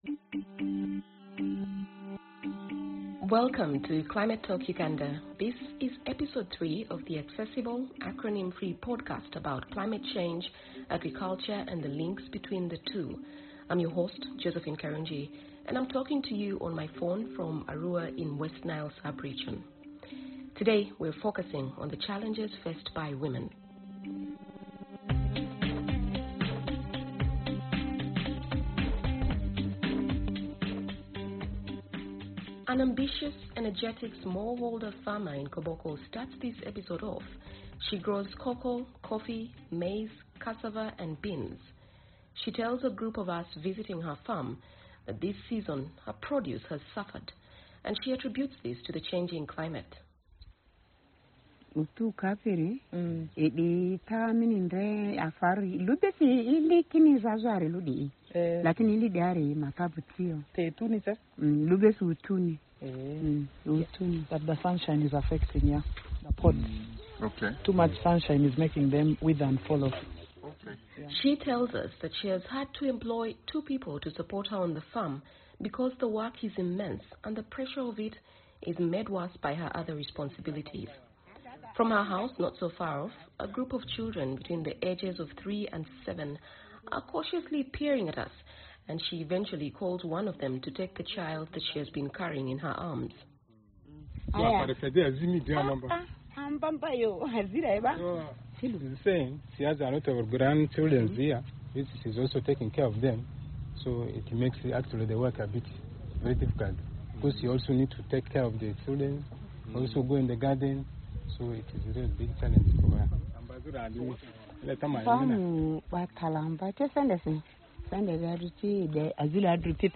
We begin this episode in Koboko, about 55 miles north of Arua, where we hear from two women about how they balance their many obligations, and how climate chance is making that more challenging.